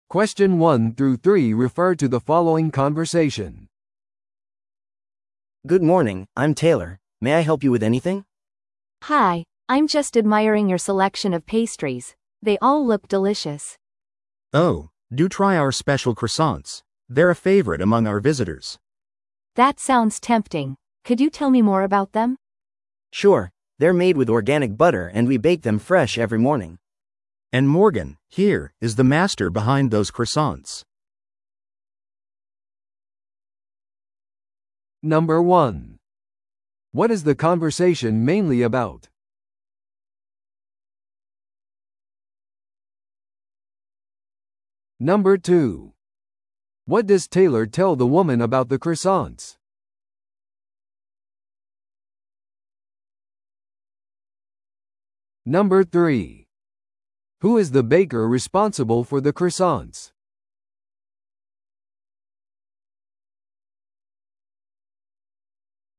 No.1. What is the conversation mainly about?